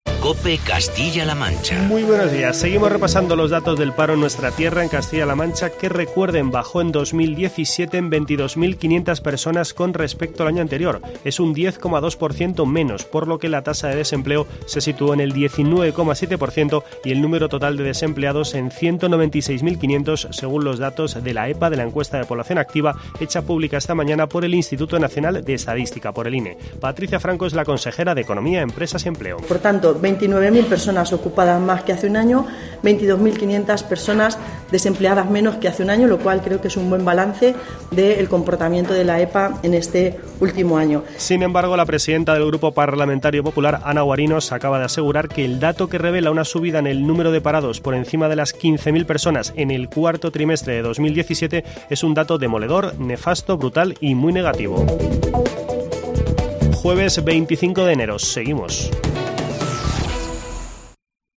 Boletín informativo de COPE Castilla-La Mancha de las 11:00 horas de este jueves, 25 de enero, de 2018.